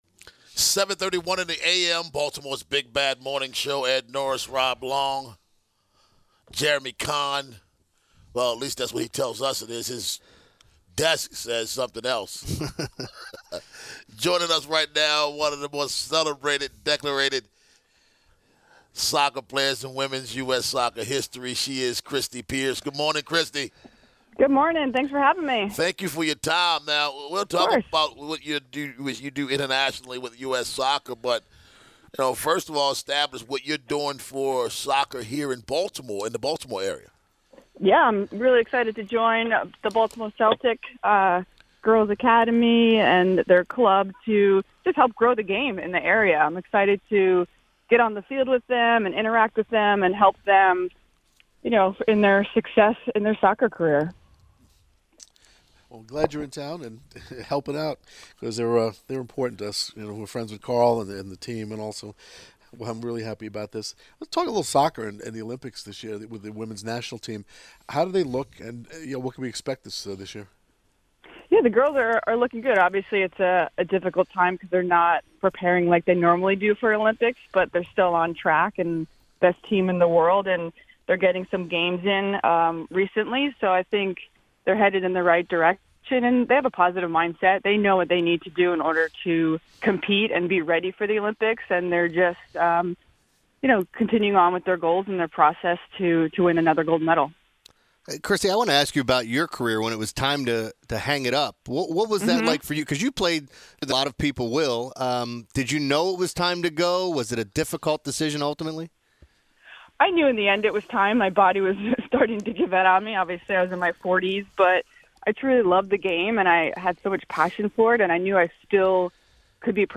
LISTEN: Baltimore Celtic’s Christie Pearce Rampone Joins 105.7 Morning Show